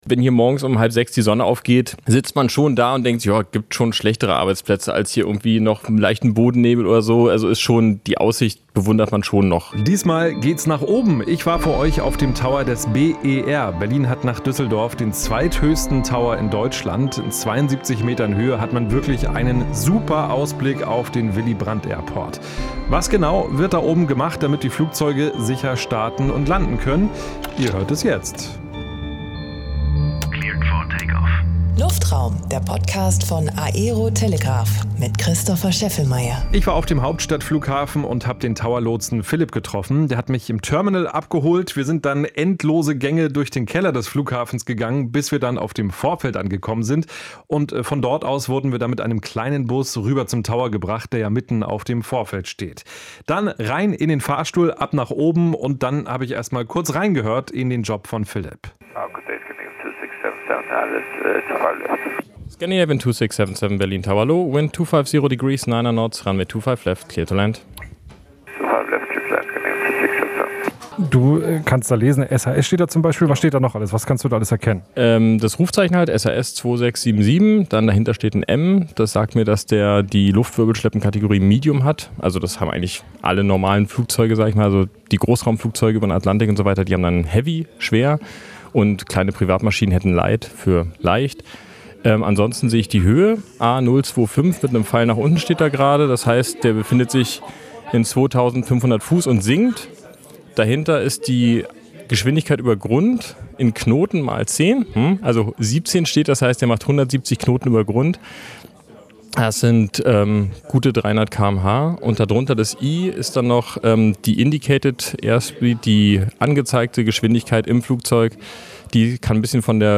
Der Tower des Berliner Flughafens ist der zweithöchste in Deutschland. In unserem diesem Podcast erzählt ein Lotse über seinen Alltag am Airport.